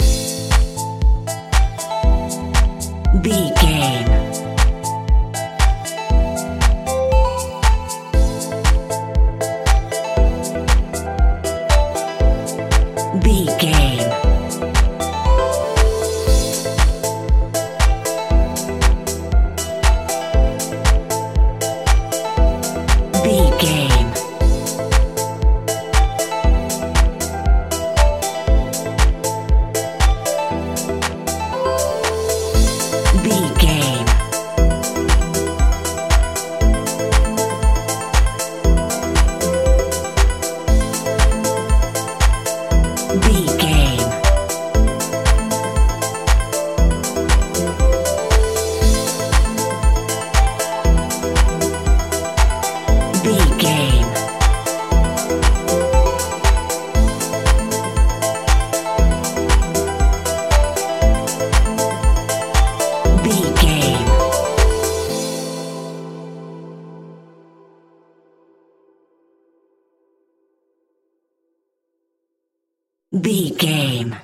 Aeolian/Minor
dark
futuristic
epic
groovy
drums
drum machine
synthesiser
electric piano
house
electro house
synth pop
funky house
instrumentals
synth leads
synth bass